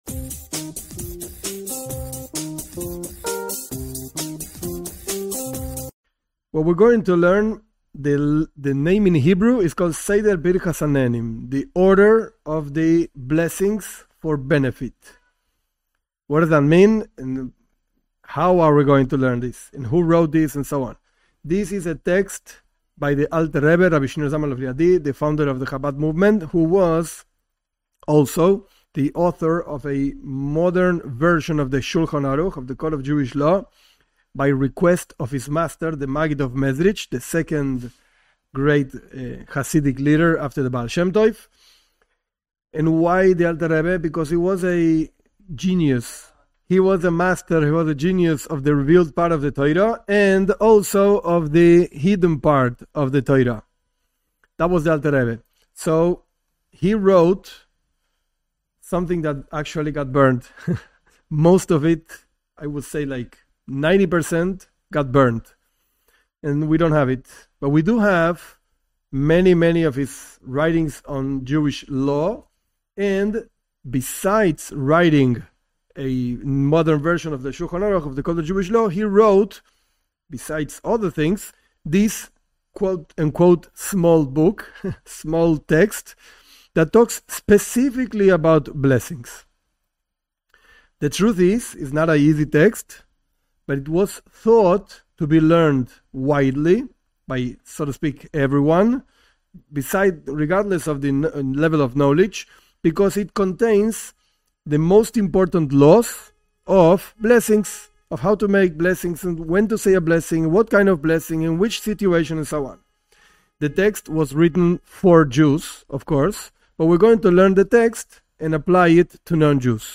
This class is an advanced level class about blessings for food, drink and others for non Jews. The base text is the writings of the Alter Rebbe regarding the subject, adapted for Bnei Noach.